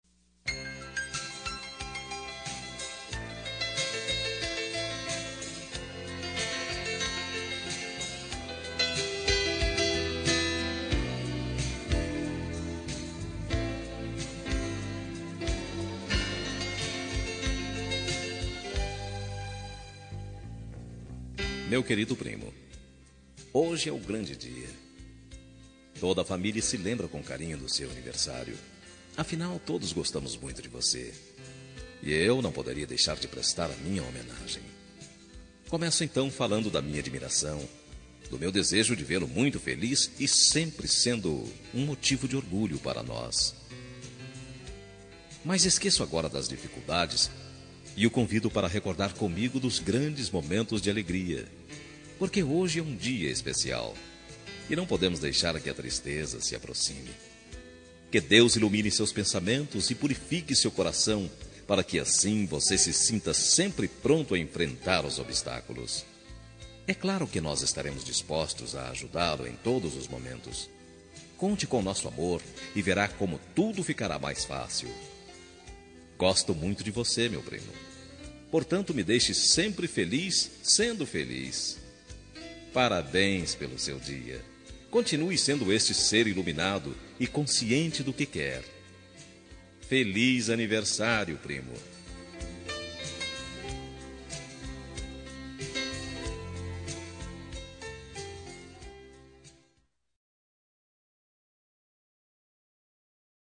Aniversário de Primo – Voz Masculina – Cód: 042830